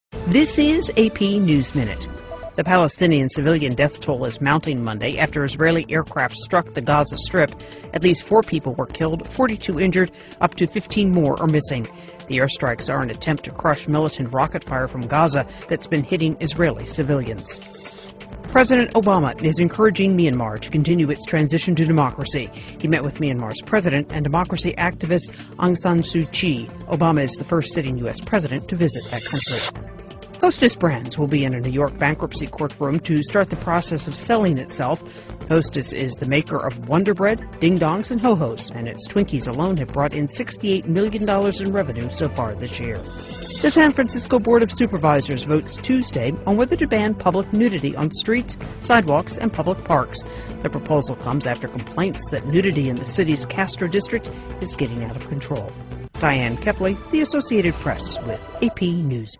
在线英语听力室美联社新闻一分钟 AP 2012-11-22的听力文件下载,美联社新闻一分钟2012,英语听力,英语新闻,英语MP3 由美联社编辑的一分钟国际电视新闻，报道每天发生的重大国际事件。